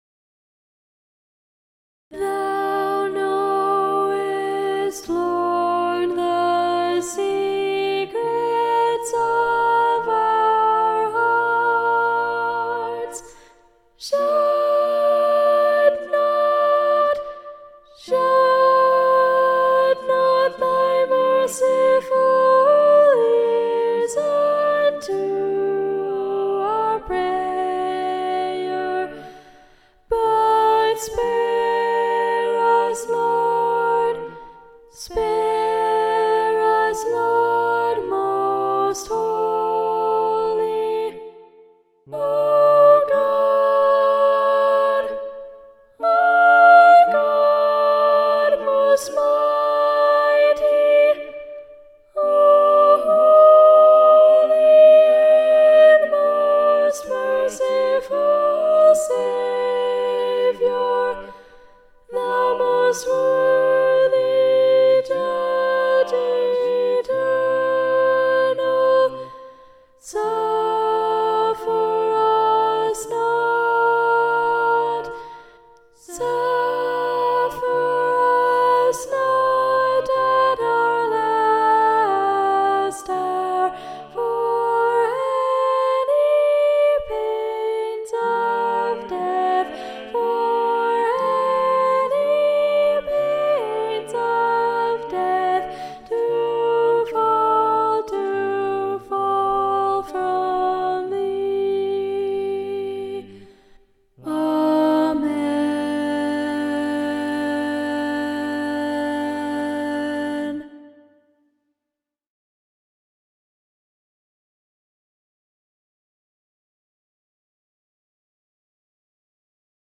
Fall 2025 Rehearsal Tracks
Music For the Funeral of Queen Mary (Thou knowest, Lord) SATB - Soprano Predominant - Henry Purcell.mp3